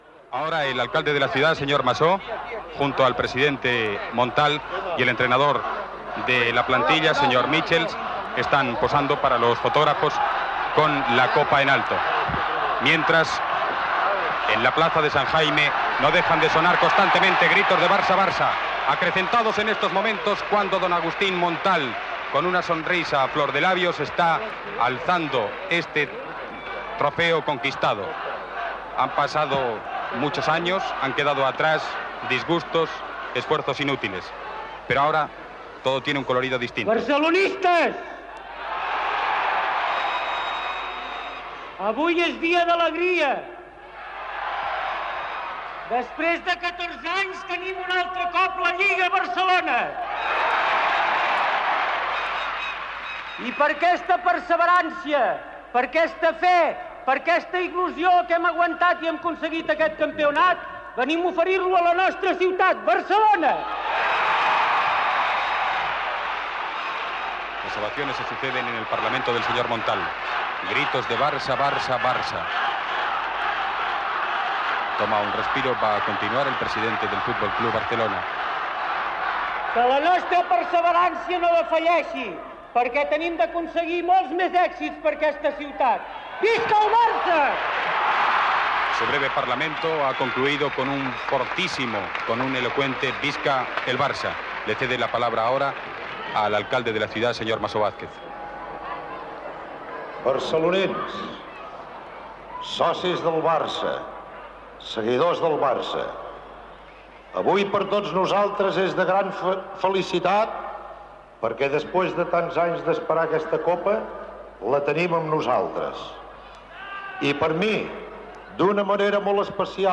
Narració. des de la Plaça Sant Jaume a la festa de celebració de la lliga 1973/1974 guanyada pel F.C:Barcelona (després de 14 anys). Parlaments d'Agustí Montal, president del club i d'Enric Masó, alcalde de Barcelona.
Esportiu